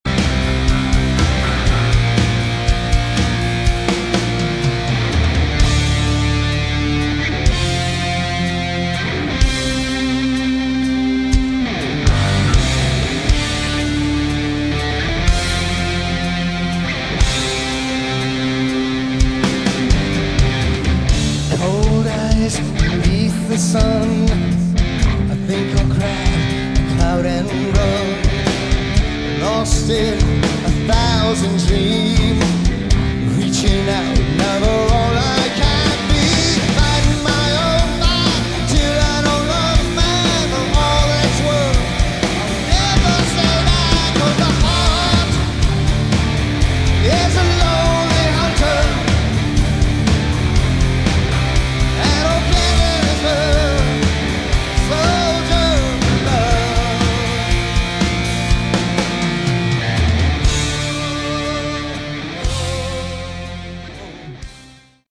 Category: AOR
guitar, vocals
drums
vocals
bass, vocals
keyboards
So awesome to see this legendary aor band on stage.